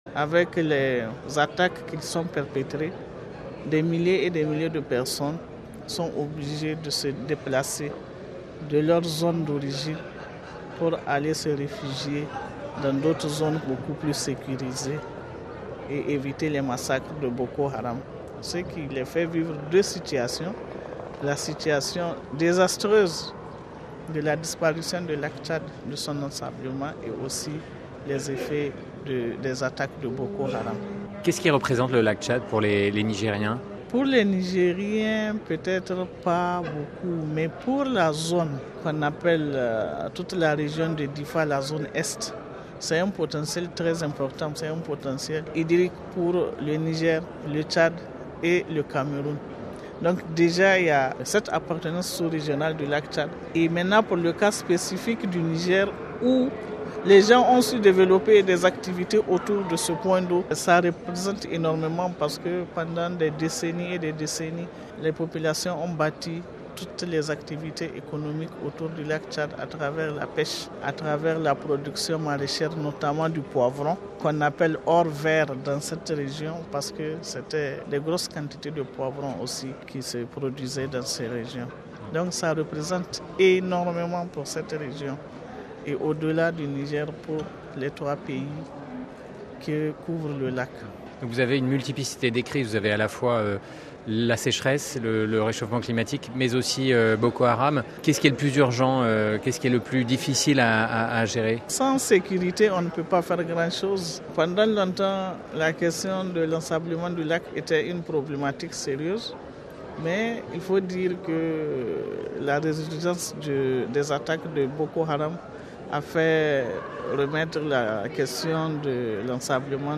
Elle est interrogée